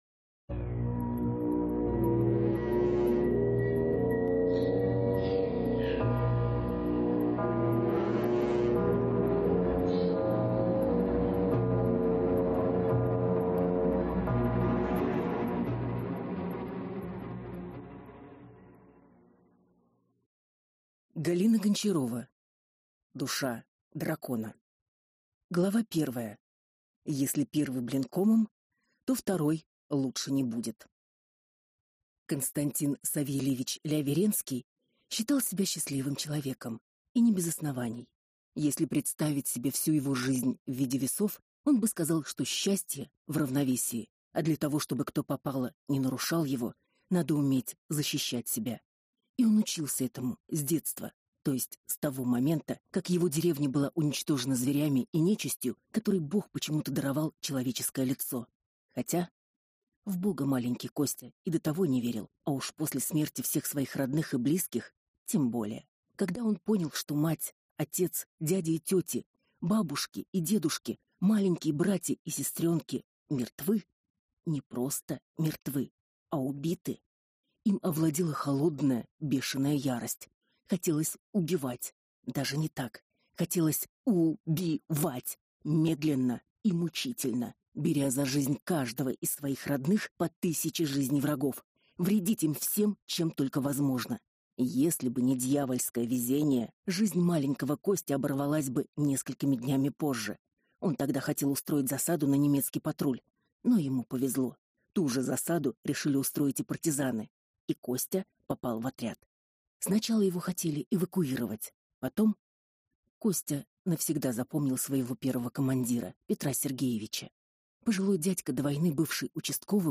Аудиокнига Душа дракона | Библиотека аудиокниг